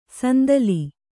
♪ sandali